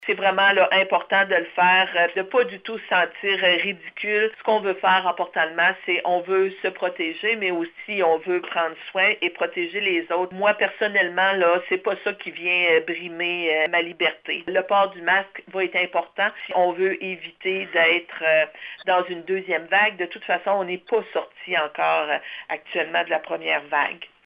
Depuis le début de la pandémie, la ministre de la région a pris l’habitude de tenir des points de presse hebdomadaires afin de tenir à jour les citoyens de sa circonscription quant aux mises à jour gouvernementales concernant la COVID-19 et ses enjeux.  Cette semaine, Diane Lebouthillier encourage les gaspésiens à porter le masque en public, lorsque la distanciation physique s’avère plus difficile.